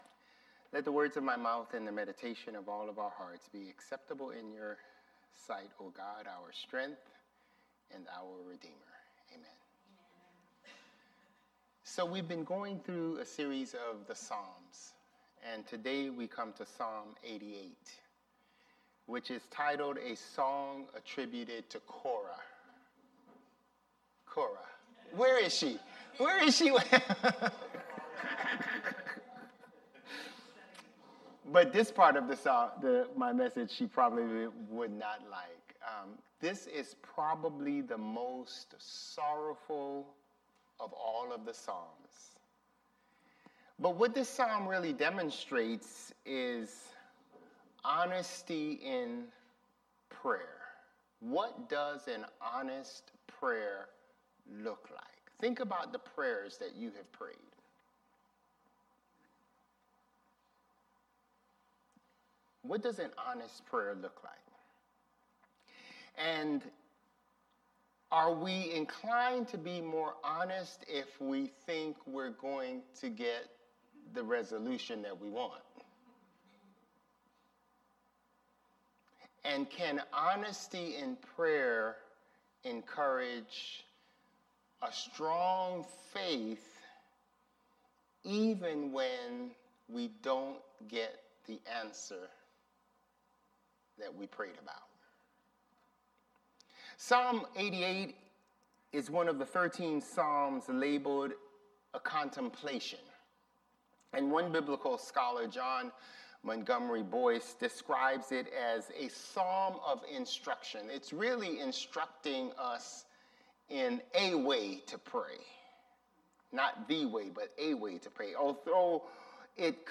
Sermons | Bethel Lutheran Church
November 9 Worship